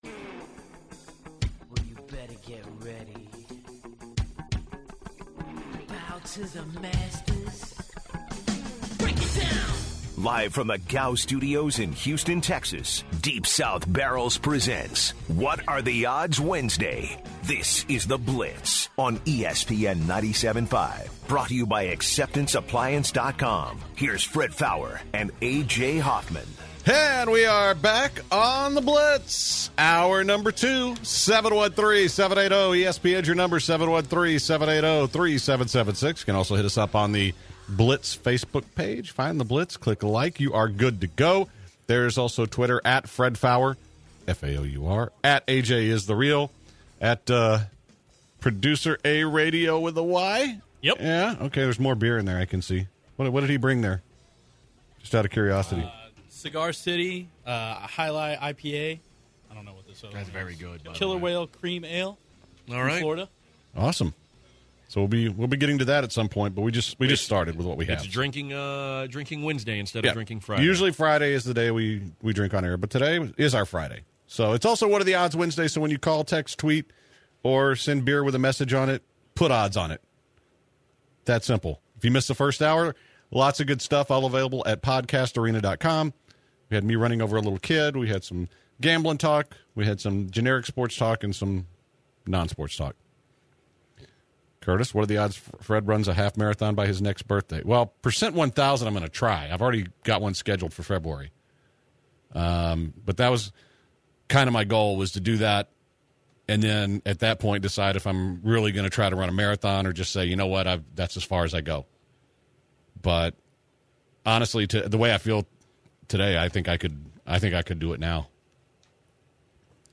The second hour started off with some college football talk, including the Robert Nkemdiche synthetic marijuana situation and if it will effect his draft status. Some What Are The Odds calls came in about Tom Herman and if Andre Johnson will be on a roster next year.